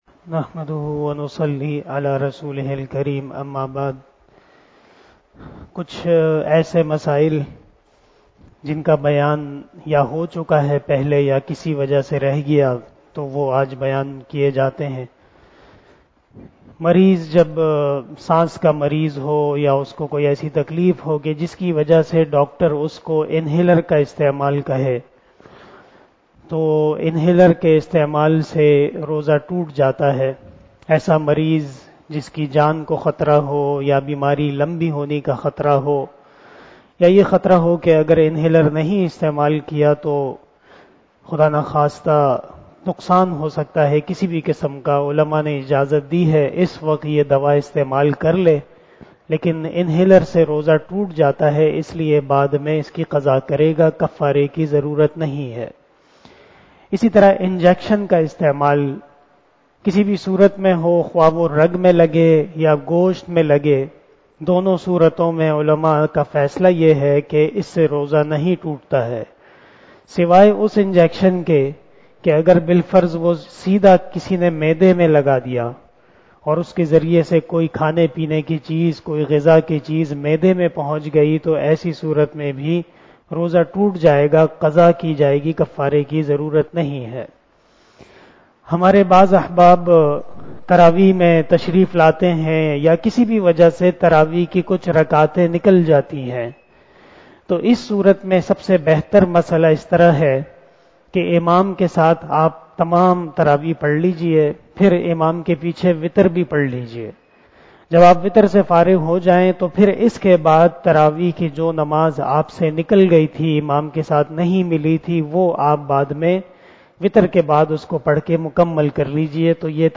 042 After Traveeh Namaz Bayan 15 April 2022 ( 14 Ramadan 1443HJ) Friday
بیان بعد نماز تراویح 15 اپریل 2022ء بمطابق 14 رمضان المبارک 1443ھ بروز جمعہ